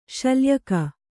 ♪ śalyaka